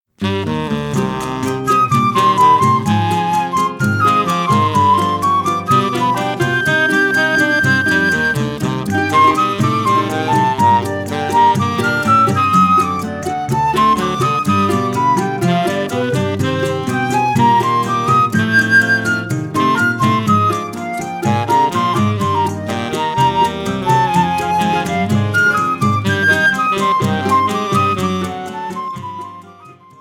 – áudio completo com regional, solo e contraponto.
flauta
saxofone tenor